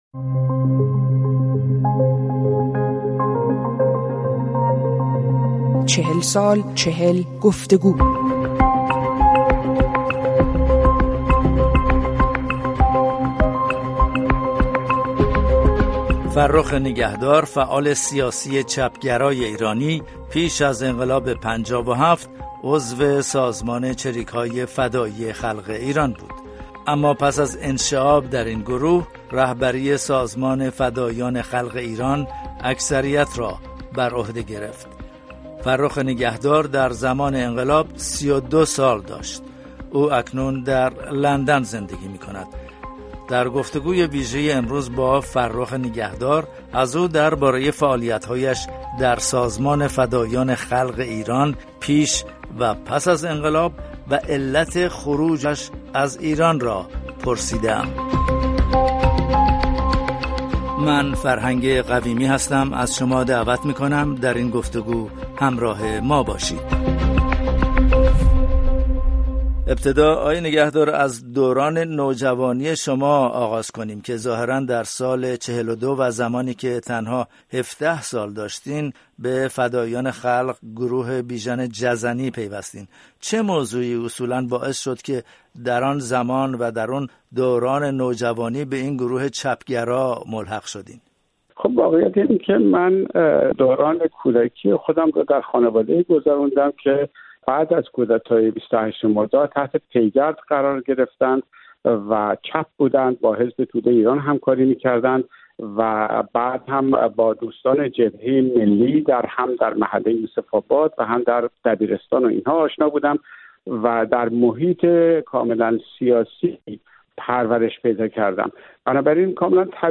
گفت‌وگو با فرخ نگهدار